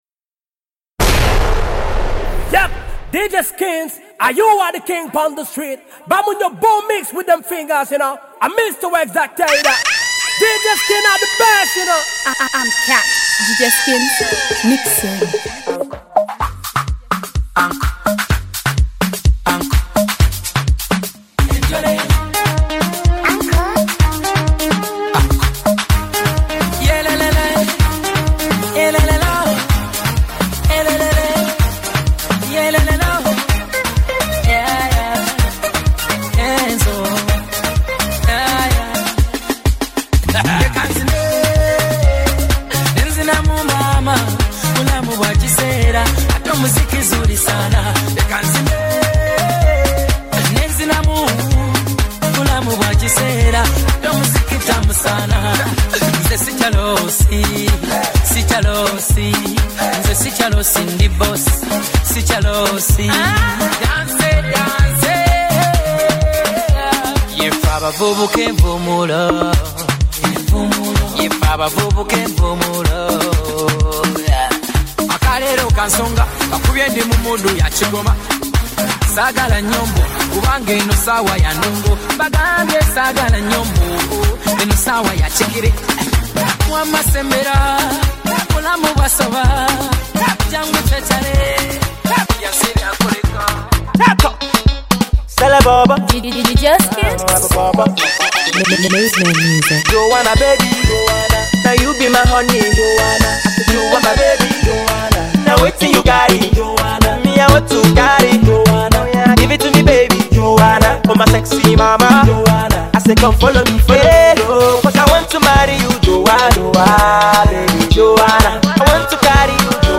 Genre: DJ MIXES.